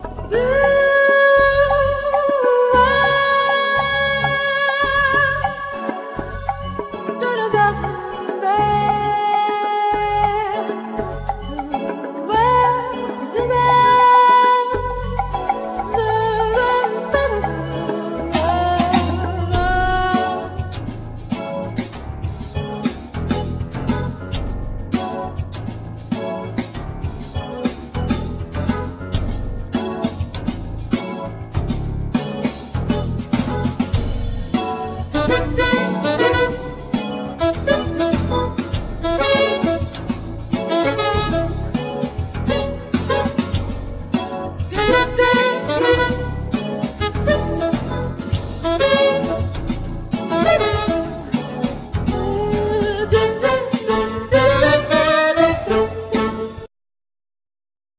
Trumpet,Flugelhorn
Soprano+Tenor Saxophone
Keyboards,Piano,Percussions
Bass
Drums,Percussions
El.guitar